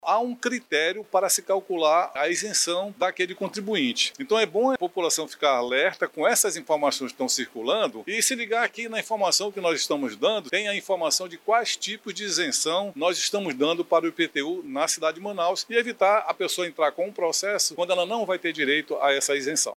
Ainda segundo o subsecretário, é importante que os contribuintes identifiquem o seu perfil e observem se estão inseridos nos critérios exigidos.